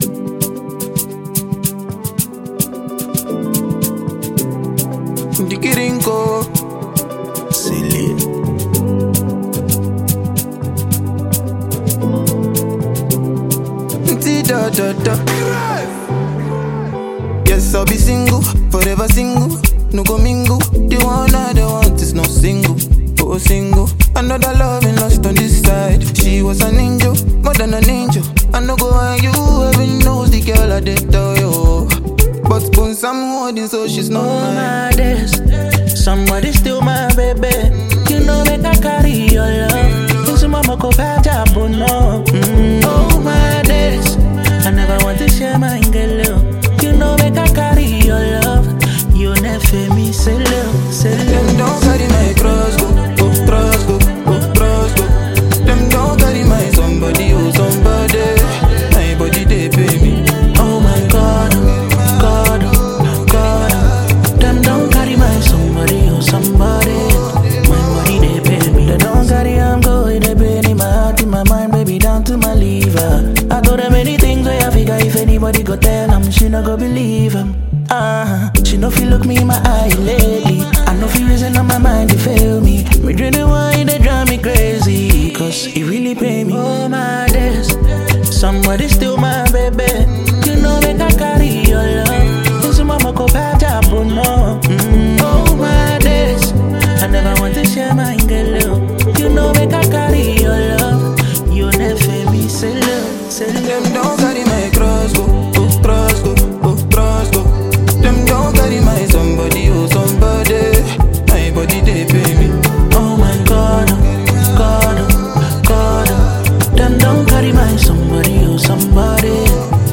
Talented Ghanaian singer-songwriter